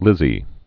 (lĭzē)